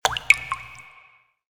Drop.ogg